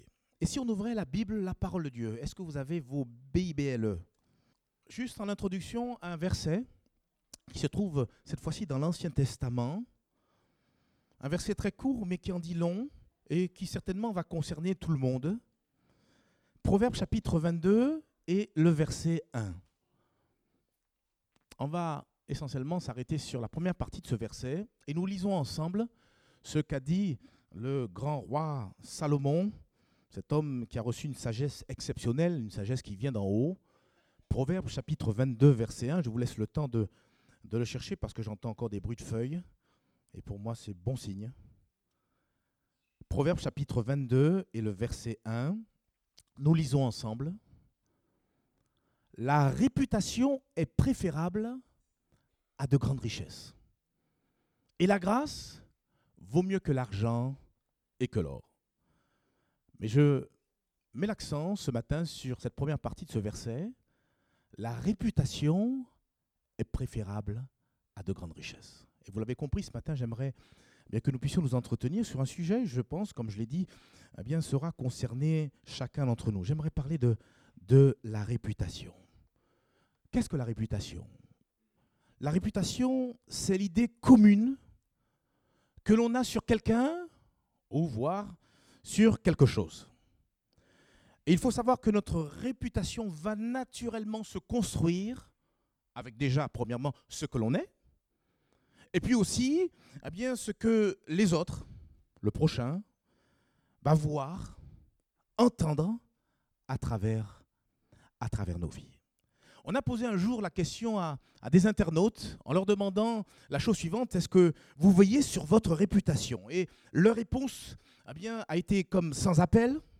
Date : 9 septembre 2018 (Culte Dominical)